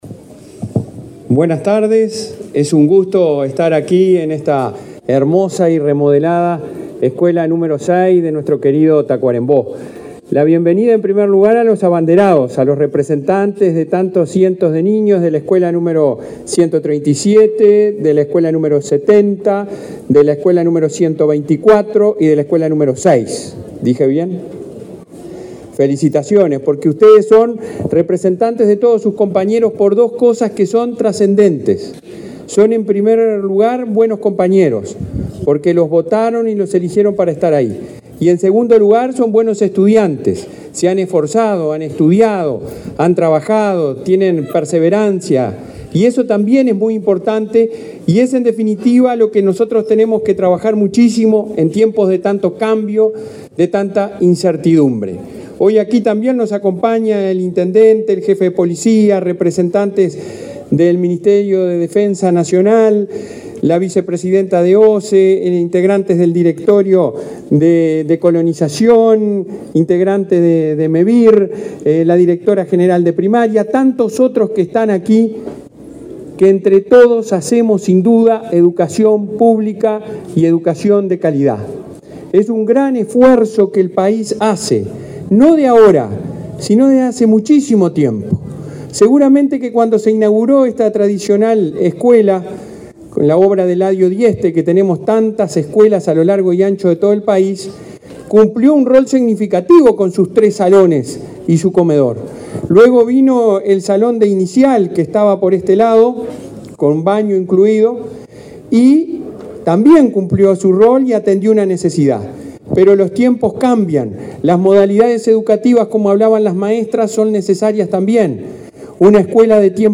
Palabras del presidente de ANEP, Robert Silva
Palabras del presidente de ANEP, Robert Silva 13/10/2023 Compartir Facebook X Copiar enlace WhatsApp LinkedIn Este viernes 13 en Tacuarembó, el presidente de la Administración Nacional de Educación Pública (ANEP), Robert Silva, participó en la inauguración de la reforma y la ampliación de la escuela n.° 6, en la capital de ese departamento.